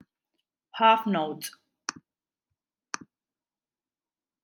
BLANCA HALF NOTE /hɑːf nəʊt/